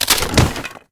woodSnap.ogg